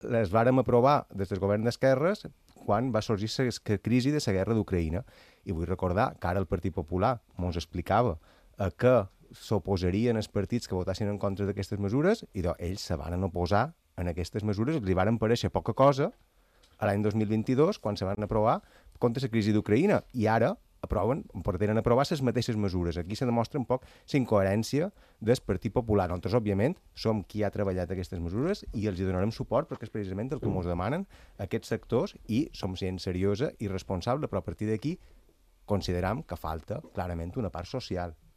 El diputat socialista Carles Bona ho ha confirmat a Al Dia d’IB3 Ràdio i ha subratllat que el decret incorpora propostes impulsades pel PSIB amb els agents socials